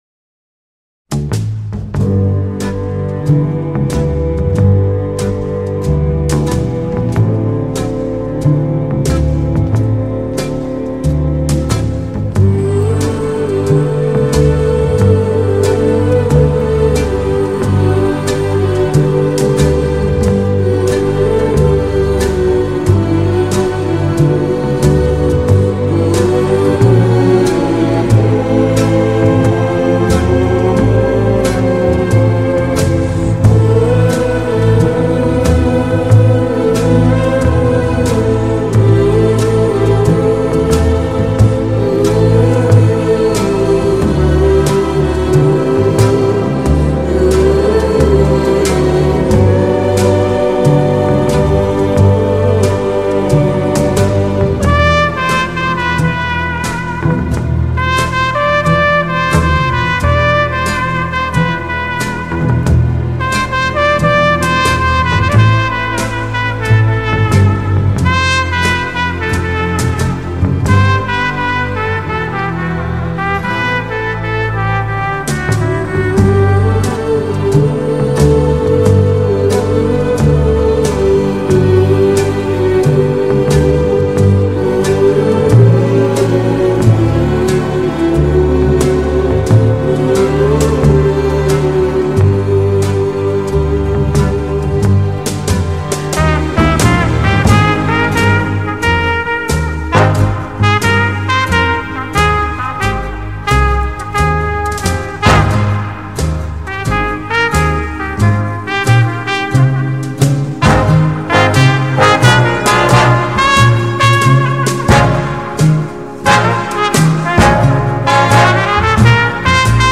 Genre: Jazz
Style: Easy Listening